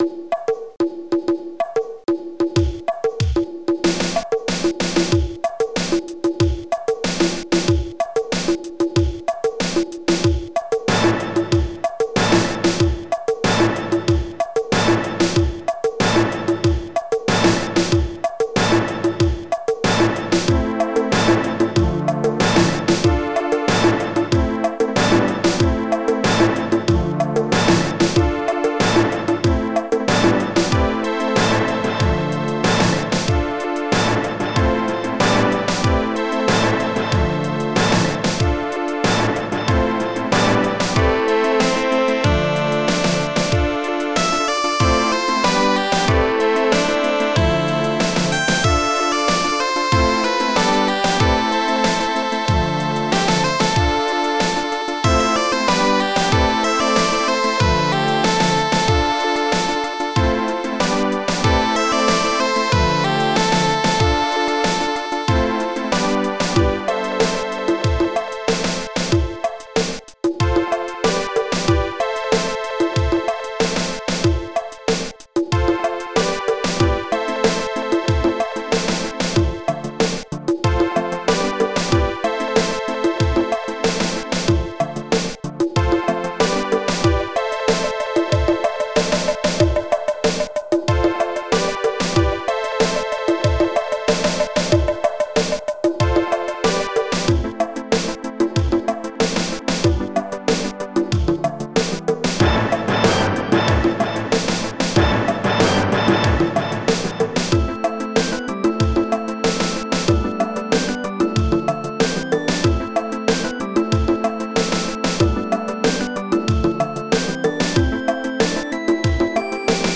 ST-30:droidsnare
ST-30:orchblast
ST-30:saxophone2